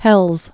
(hĕlz)